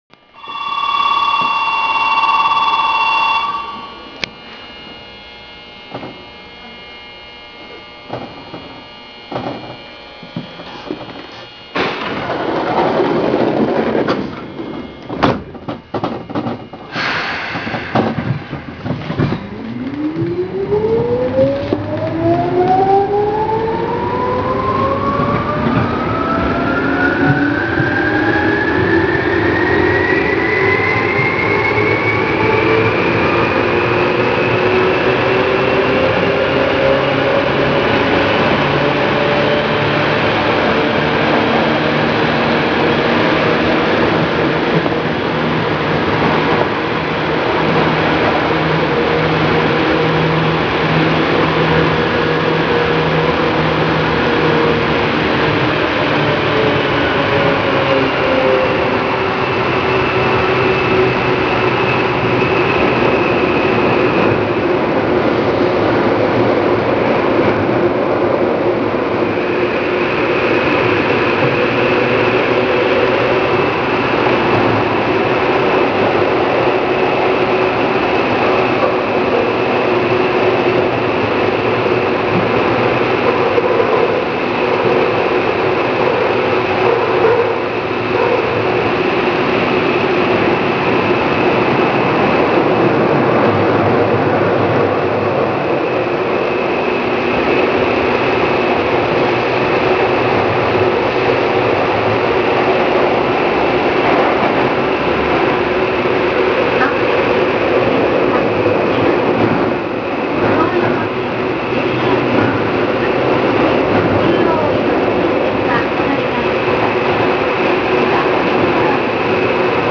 ・8590系走行音
【田園都市線】池尻大橋→渋谷（2分37秒：857KB）
とはいえ、走行音が大きいので地下区間ではイマイチよく聞こえないのですが。